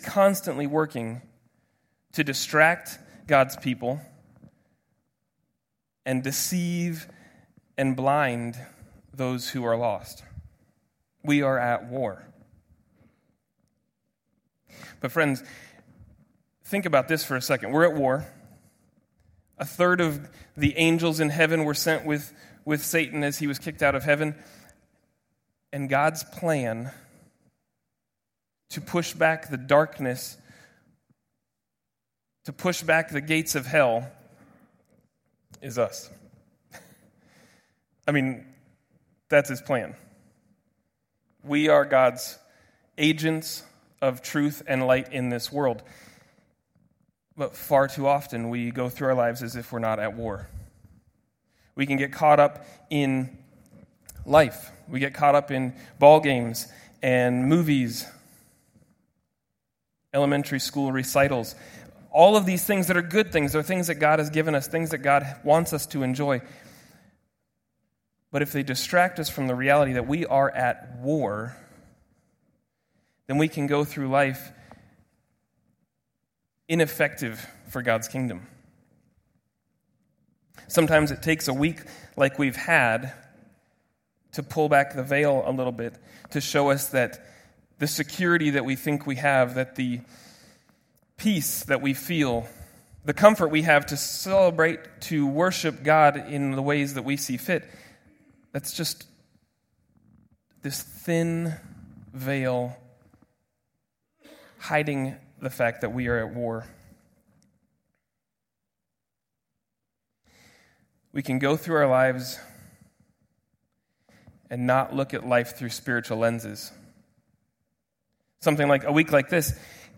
2016 Categories Sunday Morning Message Download Audio We Are at War!